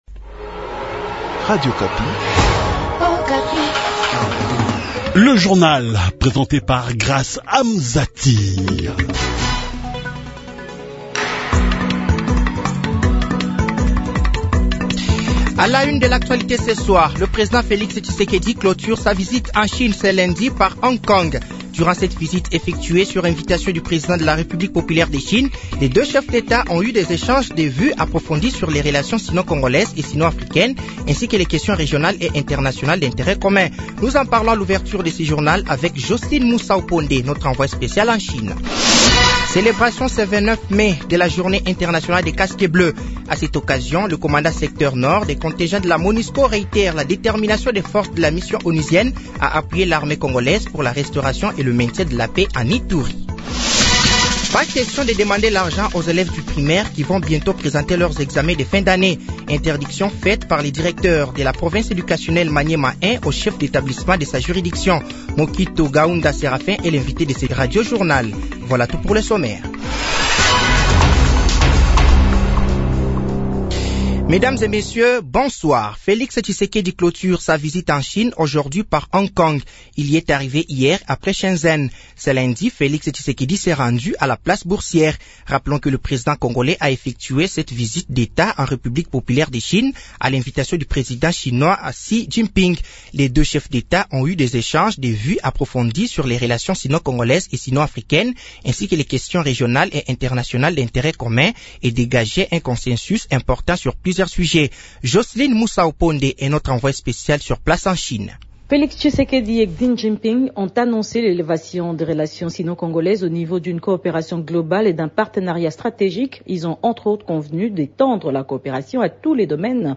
Journal français de 18 h du lundi 29/05/2023